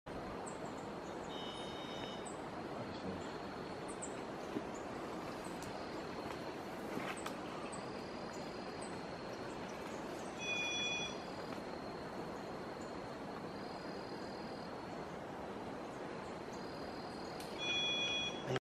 Solitario Orejiblanco (Entomodestes leucotis)
Nombre en Inglés:  White-eared Solitaire
Etapa de vida:  Adulto
Localidad o Área Protegida:  Manu Road
Condición:  Silvestre
Certeza:  Vocalización Grabada
Entomodestes-leucotis.mp3